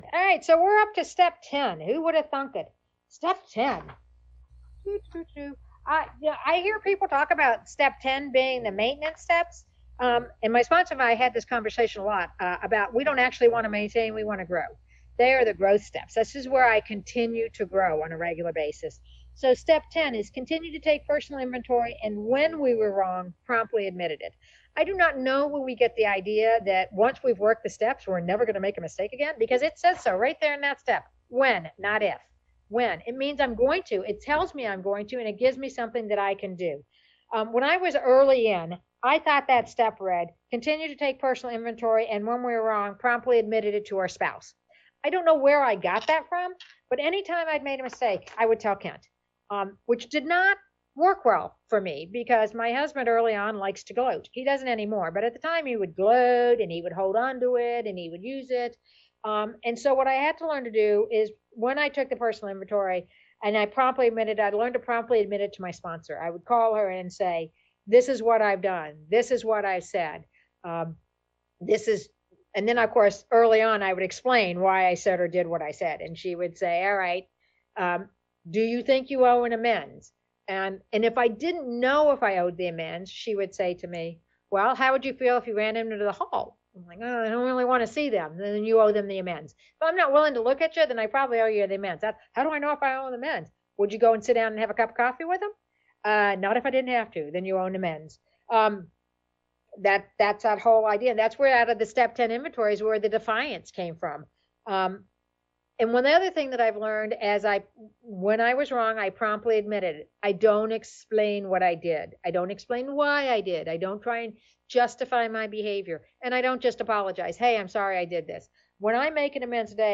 Idaho District 3 Fundraiser - Al-Anon Steps Come Alive